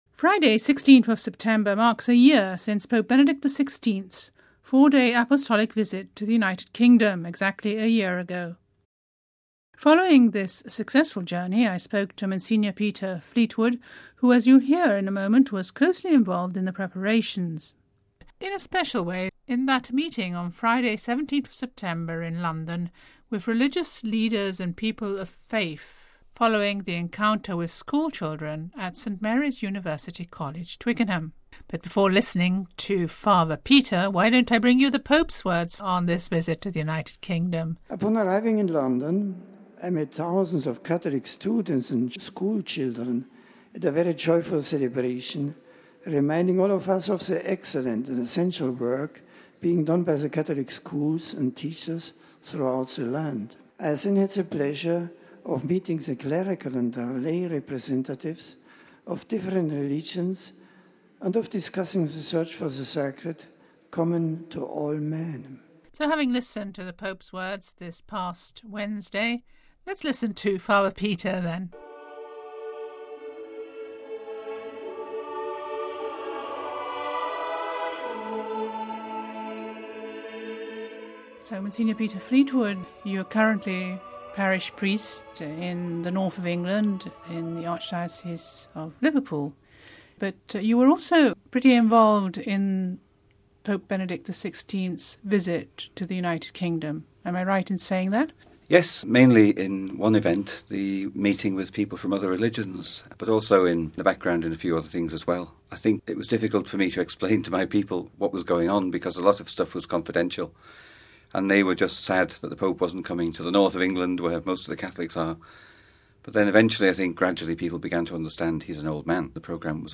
Before listening to this interview you can also hear a recording of the Popes' voice as he speaks to the faithful gathered around him in Twickenham .